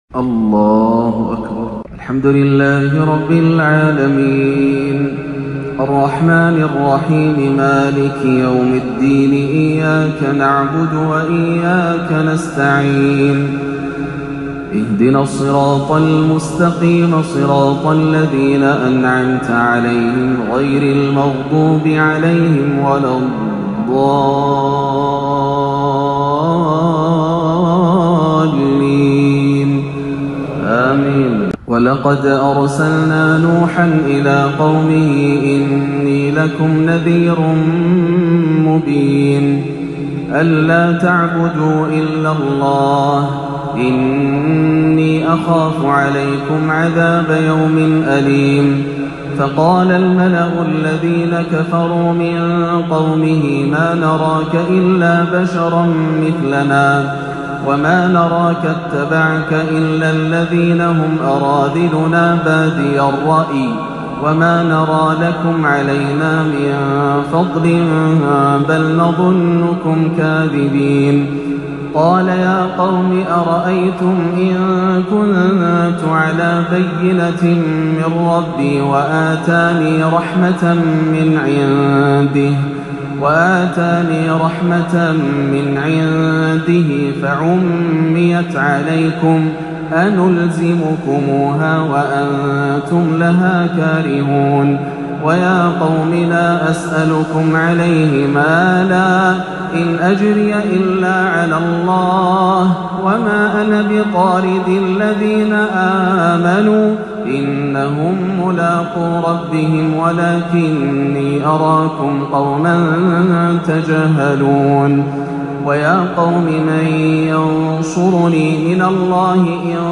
(ونادى نوح ربه) تلاوة باكية مؤثرة من سورة هود أثرت بشيخنا الغالي - عشاء الإثنين 30-12 > عام 1439 > الفروض - تلاوات ياسر الدوسري